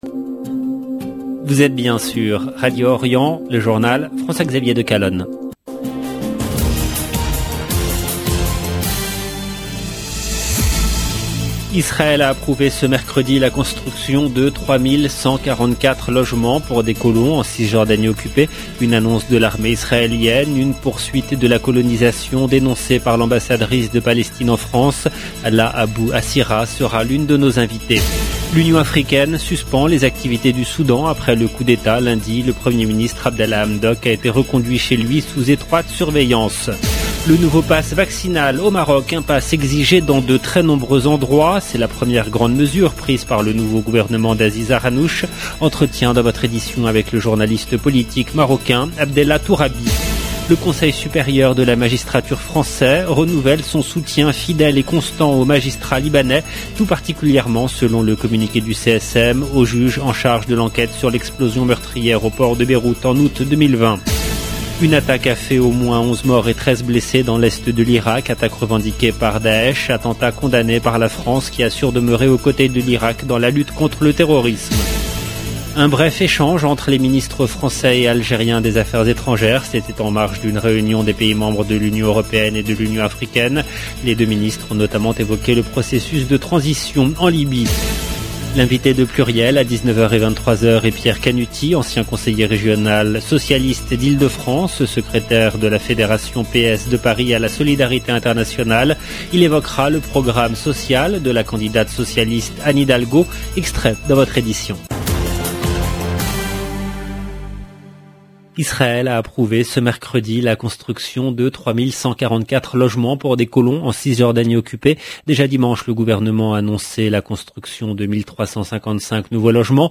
EDITION DU JOURNAL DU SOIR EN LANGUE FRANCAISE